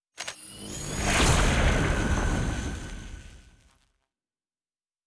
Weapon 12 Load (Laser).wav